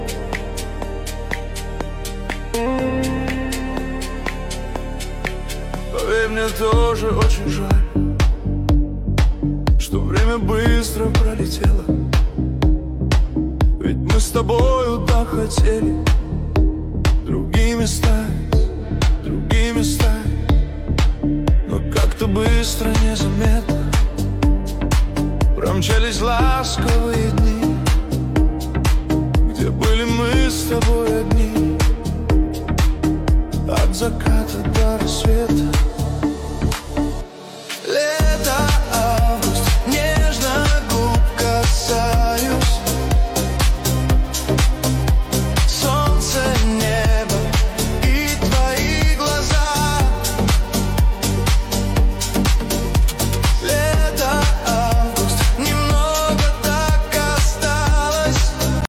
эмоциональный хит